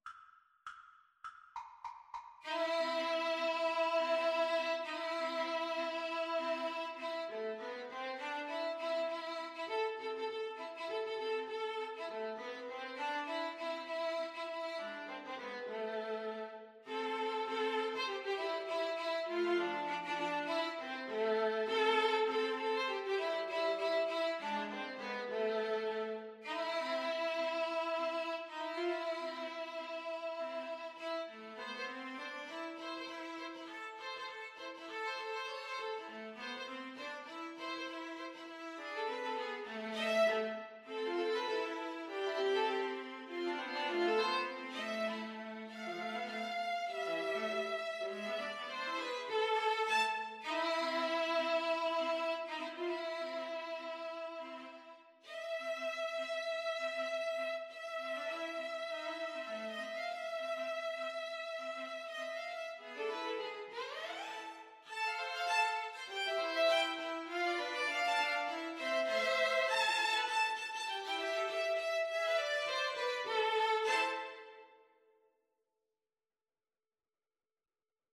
String trio version
Firmly, with a heart of oak! Swung = c.100